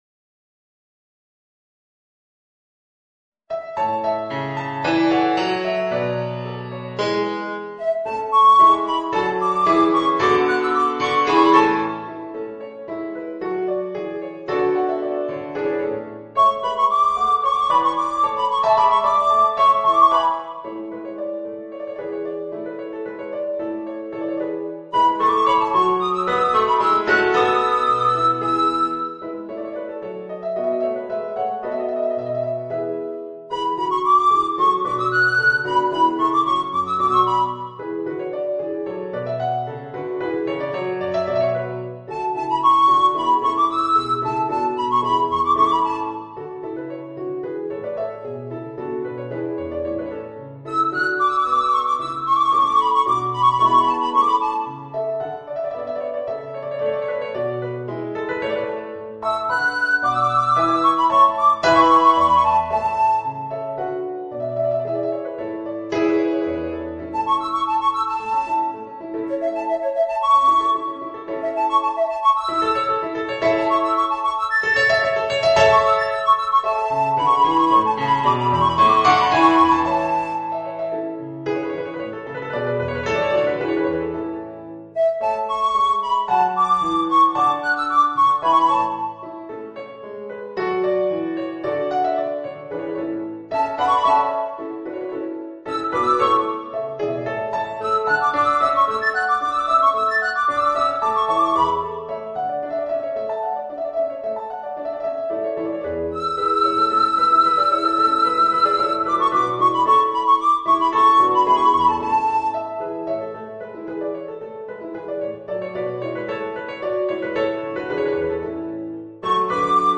Voicing: Soprano Recorder and Piano